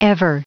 Prononciation du mot ever en anglais (fichier audio)
Prononciation du mot : ever